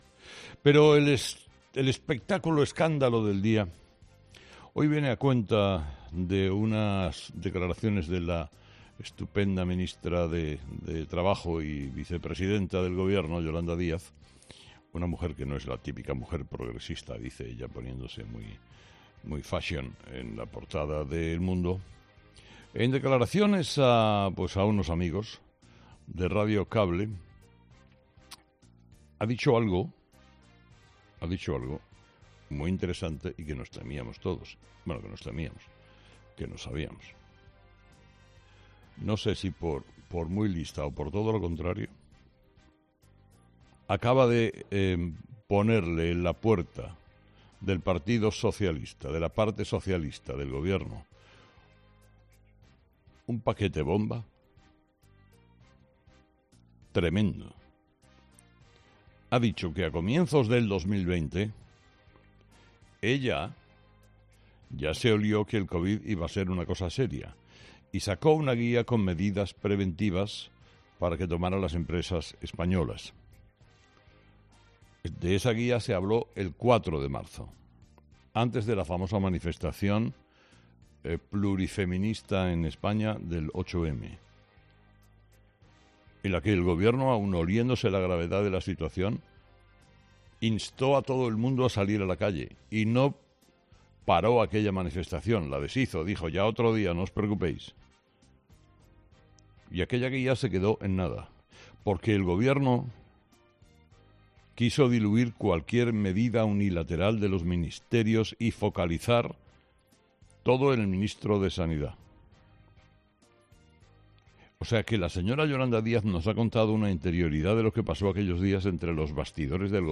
Carlos Herrera, director y presentador de 'Herrera en COPE', ha comenzado el programa de este viernes analizando las principales claves de la jornada, que pasan, entre otros asuntos, por la evolución de la nueva variante de la covid-19 y las polémicas declaraciones de la ministra Yolanda Díaz.